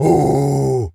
gorilla_angry_06.wav